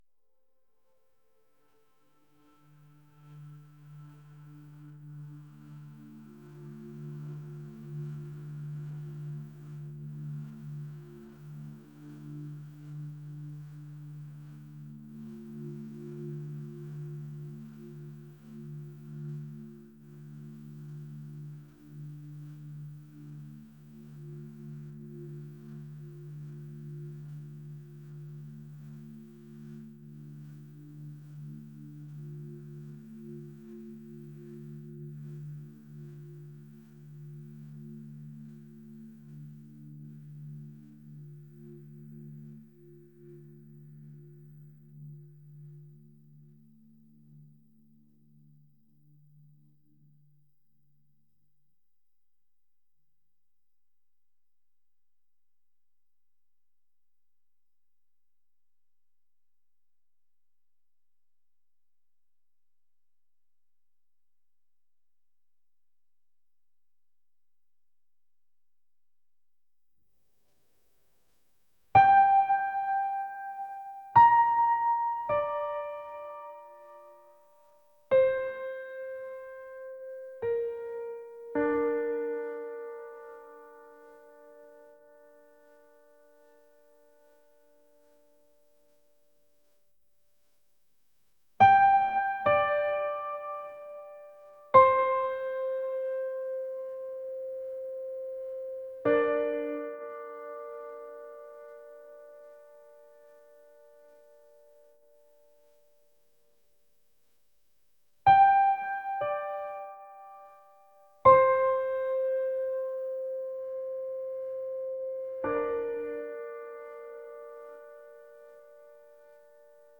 atmospheric | ethereal